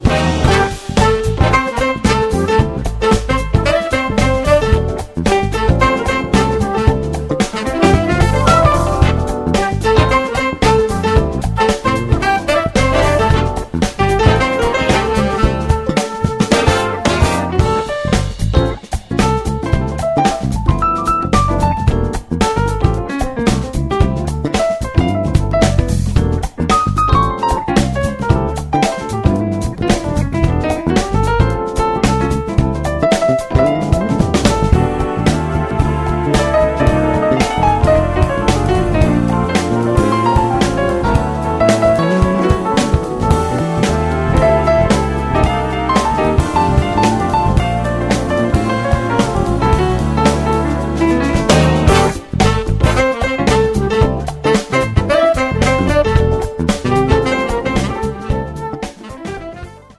Funkowe zacięcie